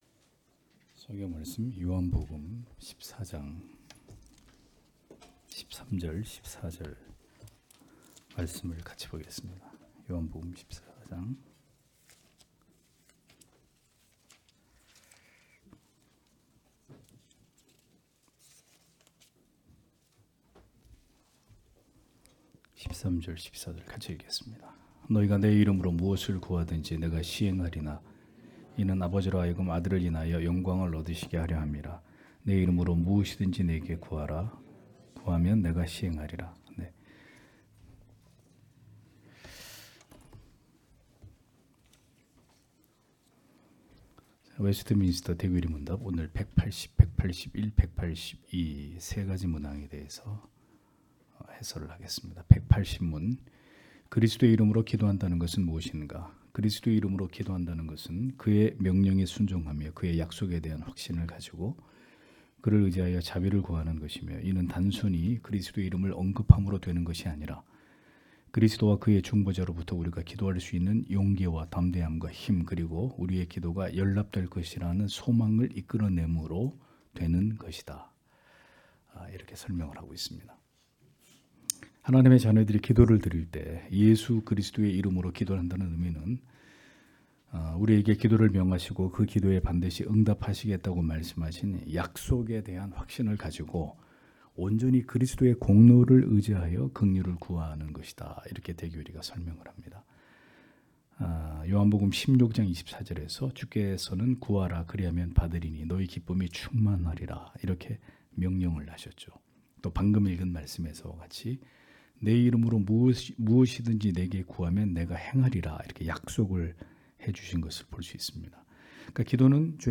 * 설교 파일을 다운 받으시려면 아래 설교 제목을 클릭해서 다운 받으시면 됩니다.